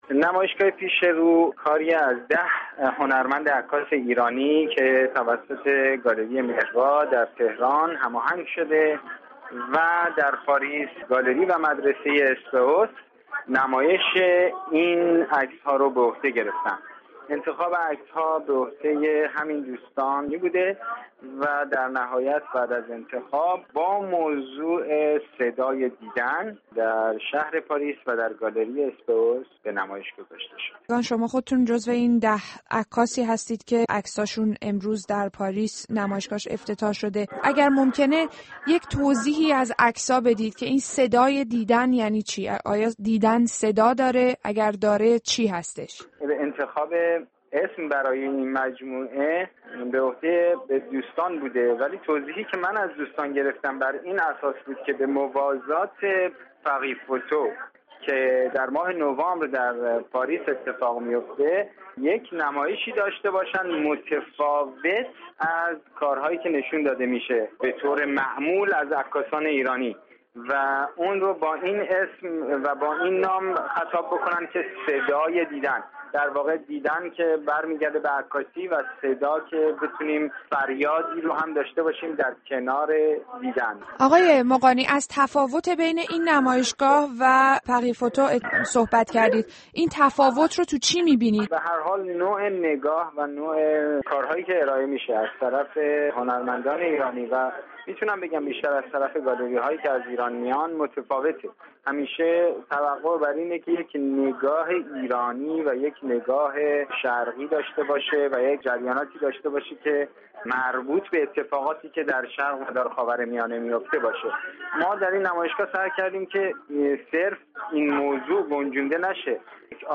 به تماشای صدا؛ عکاسی معاصر ایران در پاریس [گفت‌وگو